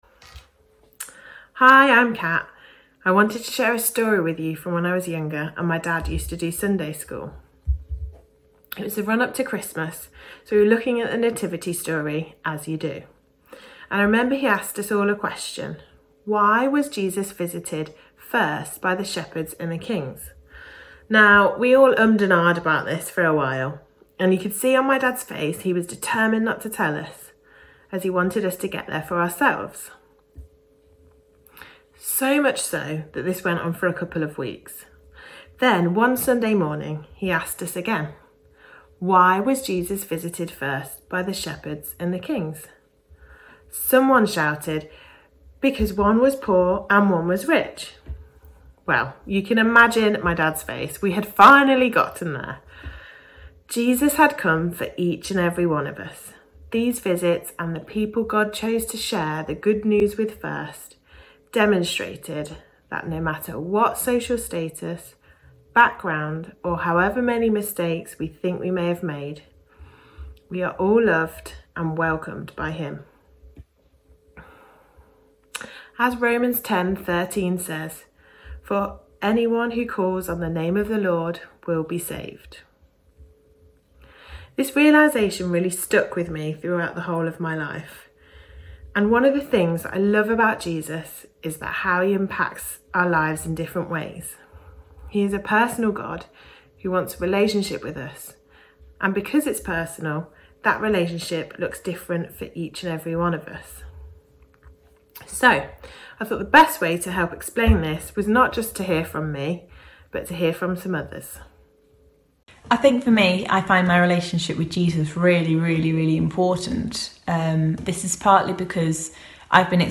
A talk from the series "Christmas 2020."